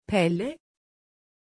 Aussprache von Pelle
pronunciation-pelle-tr.mp3